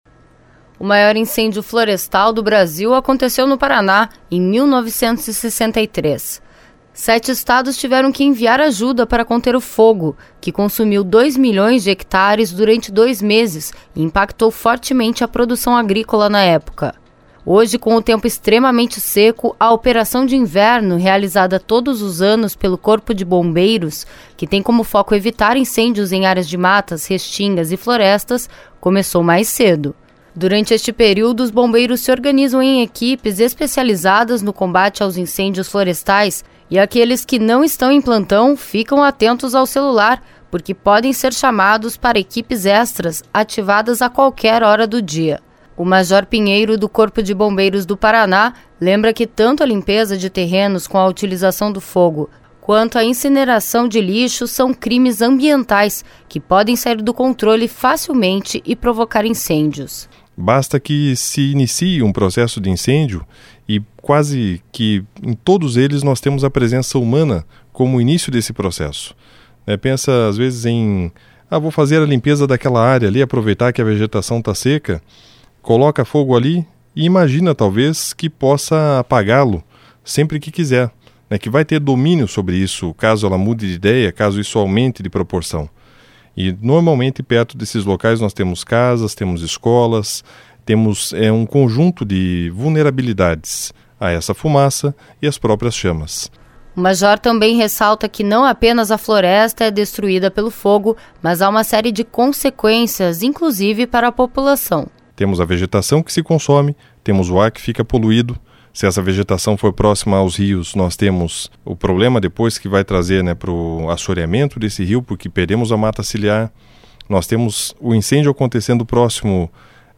O Corpo de Bombeiros do Paraná reforçou a operação de inverno deste ano, que tem como objetivo evitar incêndios florestais, por conta da estiagem que assola o estado. Confira na reportagem